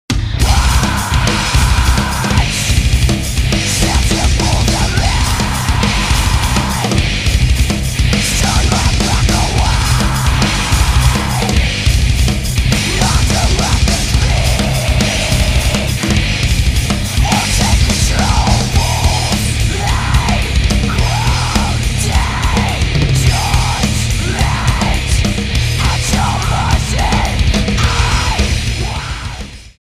STYLE: Hard Music
This is loud and intense and on the whole very good.
Hardcore with a bit of diversity.